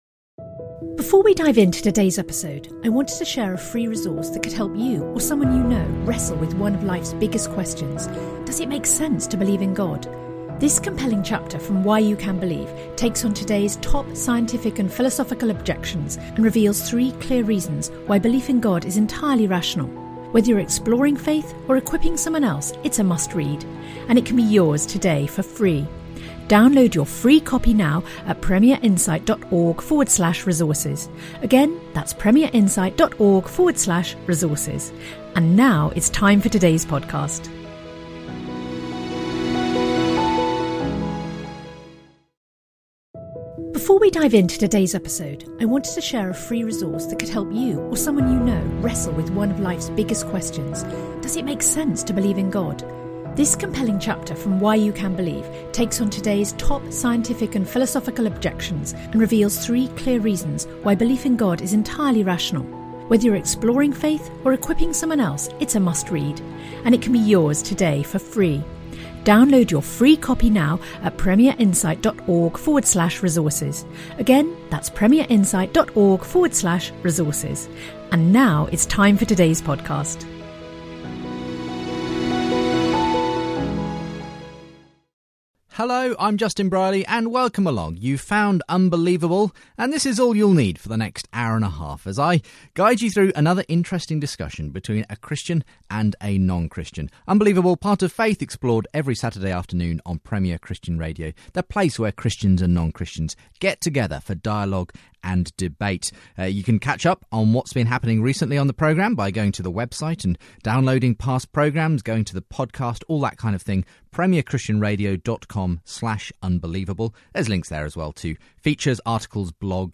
Jim interacts with Alister on whether science excludes God, whether theism makes most sense of the nature of our universe and what it would take for him to abandon his atheism and believe.